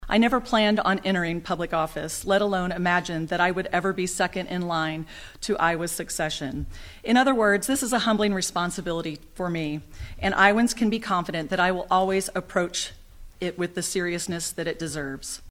COURNOYER SAYS SHE IS HUMBLED BY THE GOVERNOR’S APPOINTMENT OF HER AS LT. GOVERNOR: